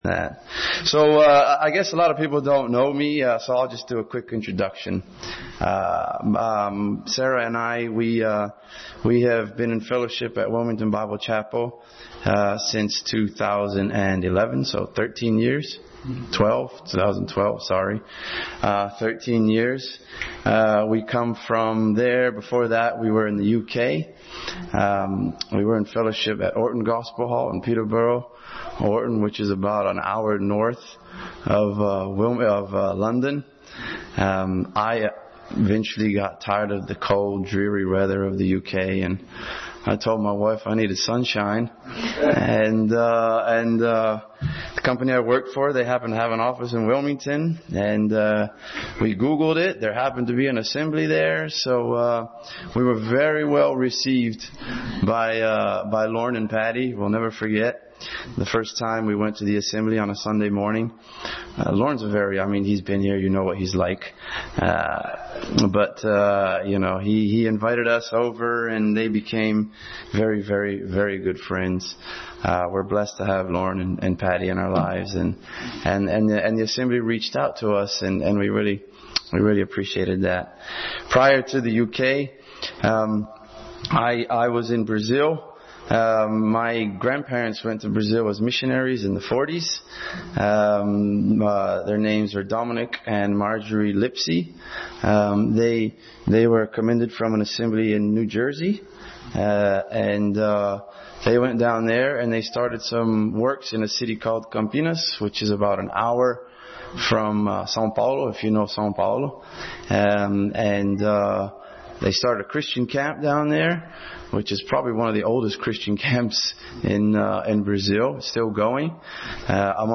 1 Peter 4:12-19 Passage: 1 Peter 4:12-19 Service Type: Sunday School